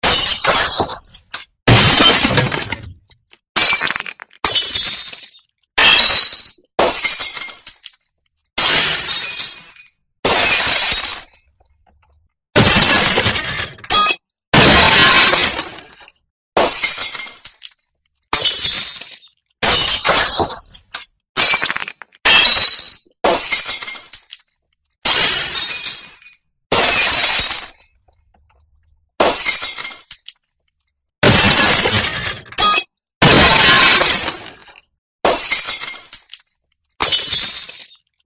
そんな感じでいろんなガラス製品を投げつけて割った効果音。